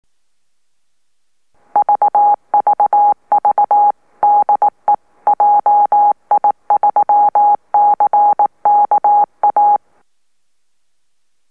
受信音にPLLのVCOノイズが少し混じっている、PLLシンセサイザ方式の限界レベルか？
回路定数とｼｰﾙﾄﾞ板の構造を変更、音調の安定度を改善した。＜2008.6.6＞
モールス符号で断続した波形